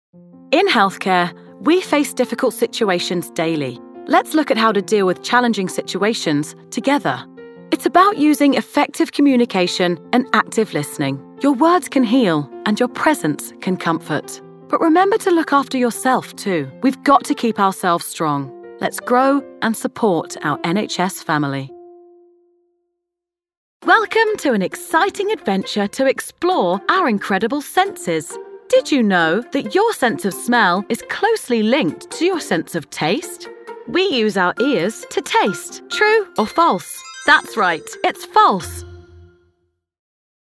Inglés (Británico)
Versátil, Amable, Natural
E-learning
Her voice is youthful, husky, relatable, and authentic, and her natural accent is a London/Estuary accent.